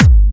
VEC3 Clubby Kicks
VEC3 Bassdrums Clubby 047.wav